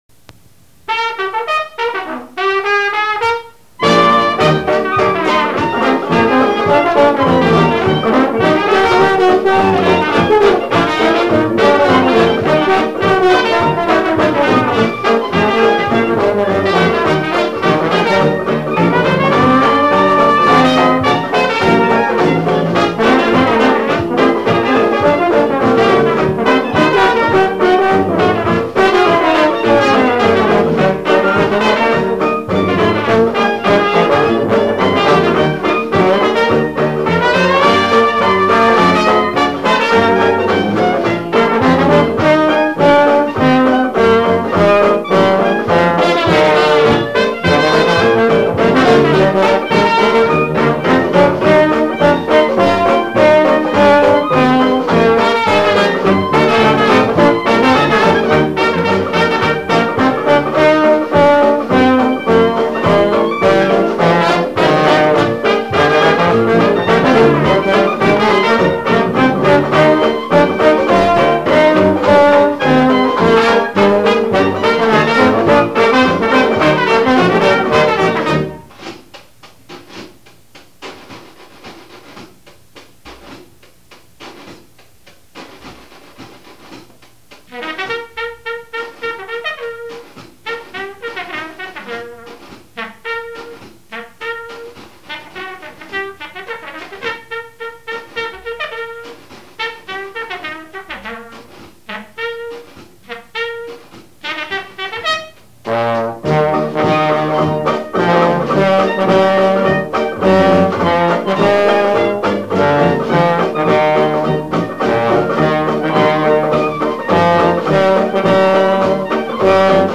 You can hear two outfits that I played with in Wilmington, Delaware.
. then one in a minor key .
trombone